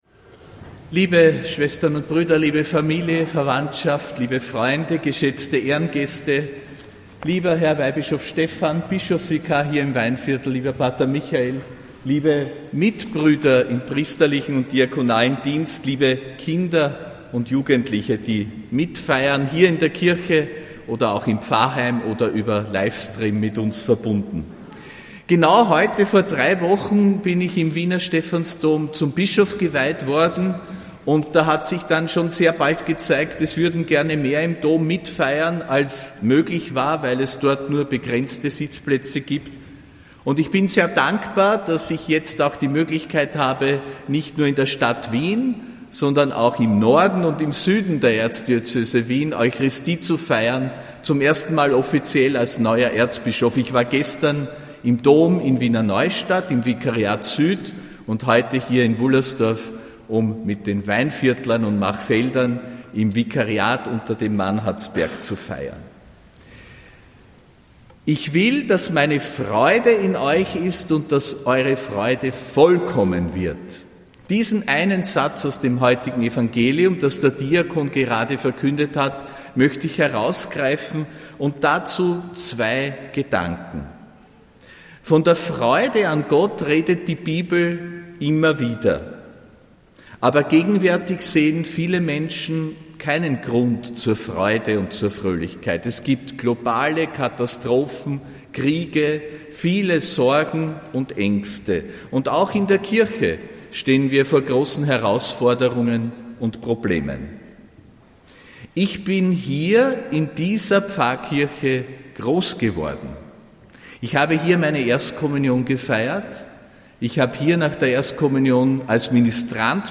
Predigt von Erzbischof Josef Grünwidl bei der Bischofsmesse in der Pfarrkirche Wullersdorf, am 14. Februar 2026.